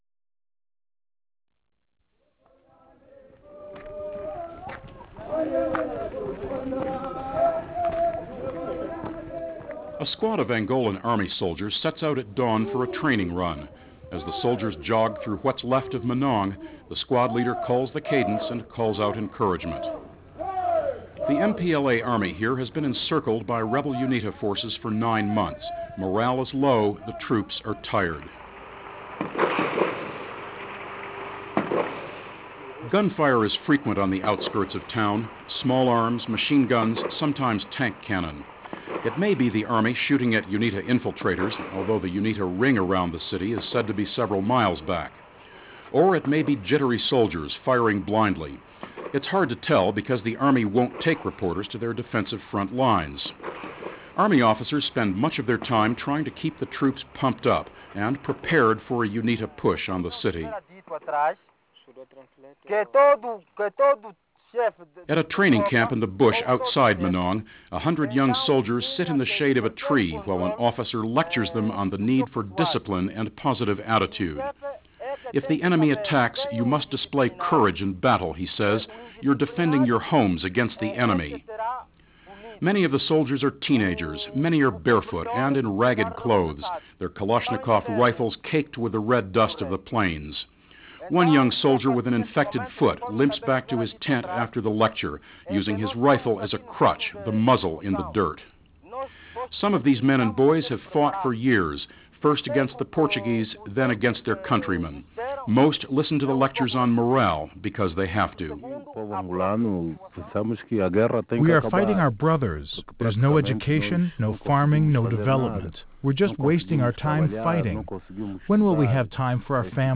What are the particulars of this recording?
The report was produced by Monitor Radio and originally broadcast in February of 1994.